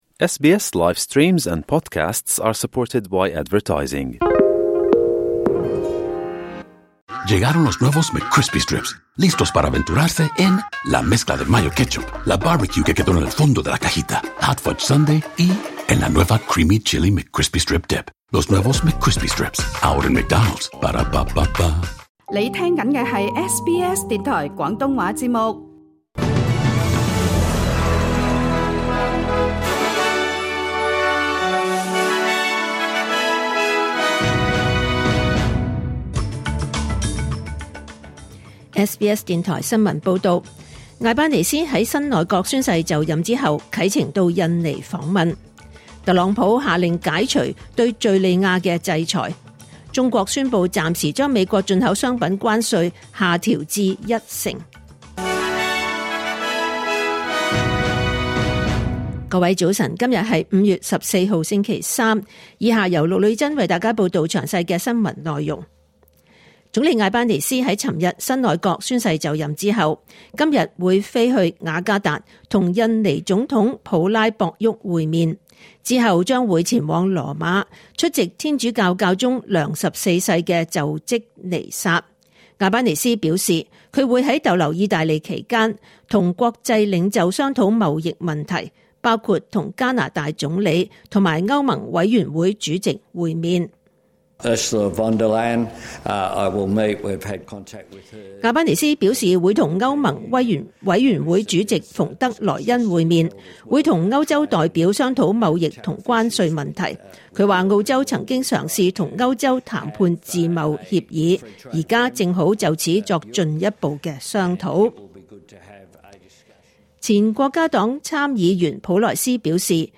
2025年5月14日 SBS 廣東話節目九點半新聞報道。